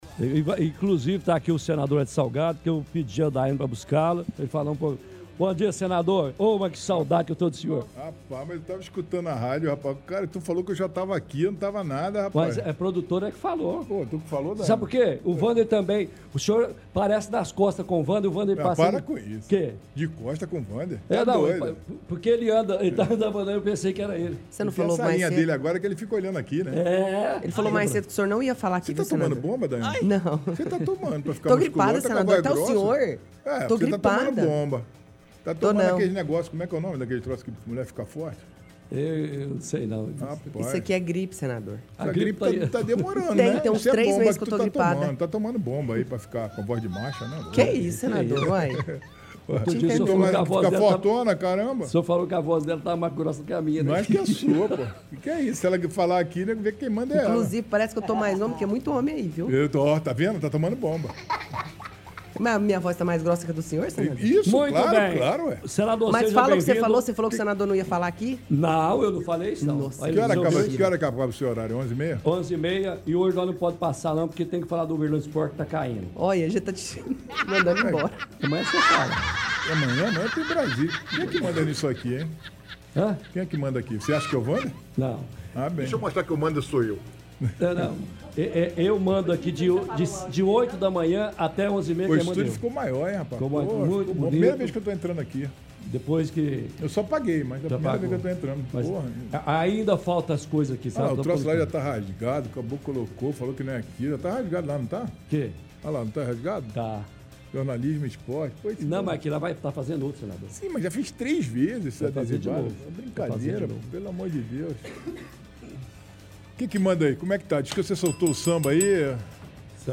Entrevista com Wellington Salgado